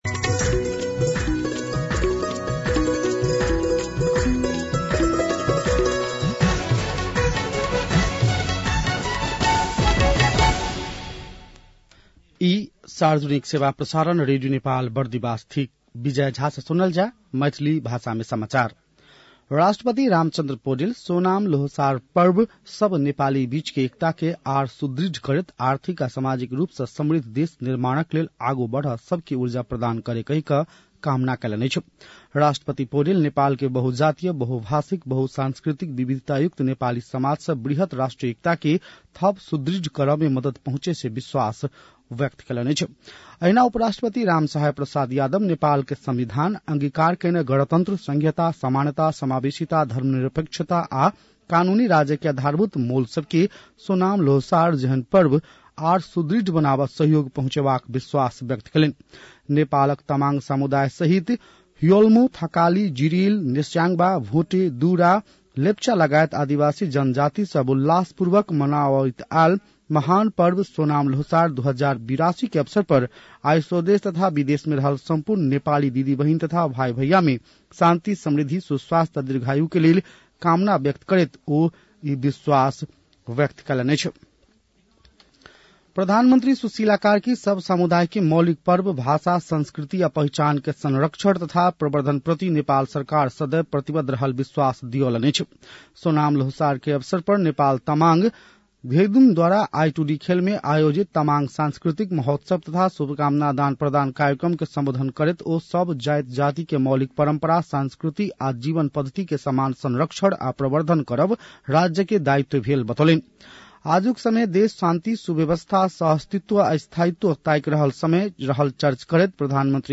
मैथिली भाषामा समाचार : ५ माघ , २०८२
6.-pm-maithali-news-1-6.mp3